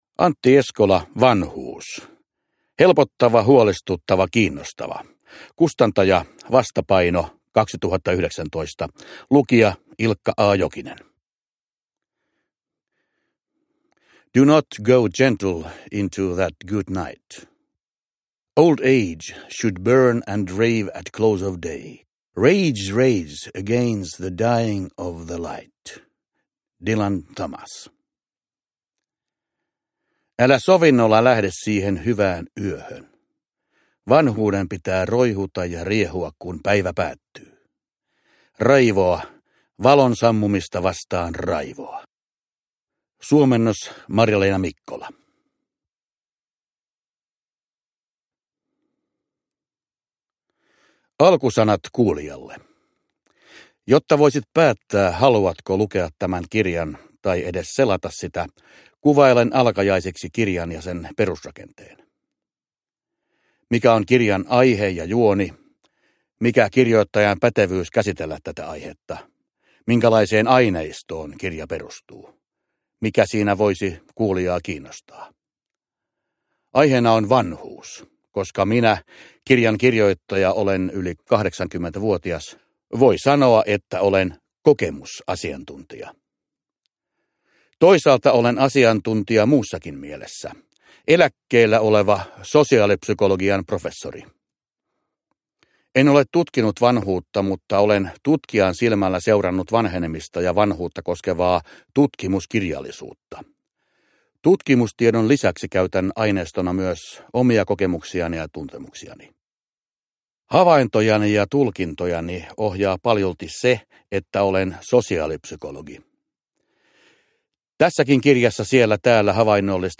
Vanhuus – Ljudbok – Laddas ner